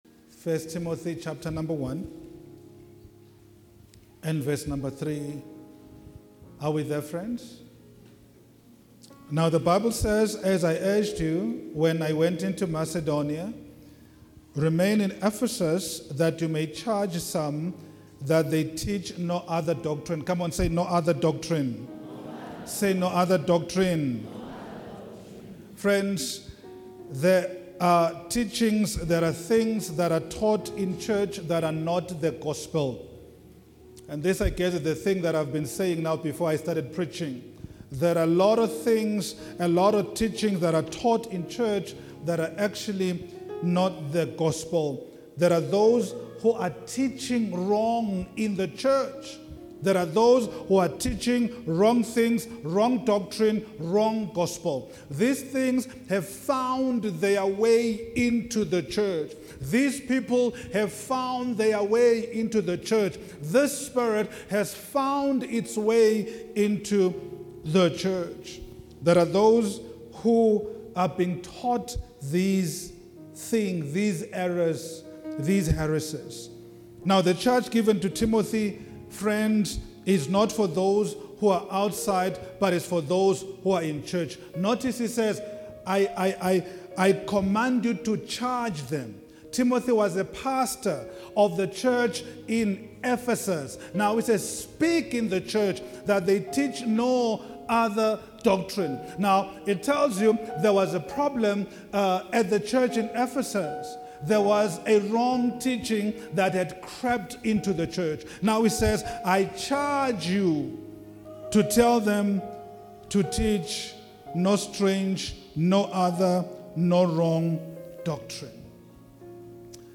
Latest Teachings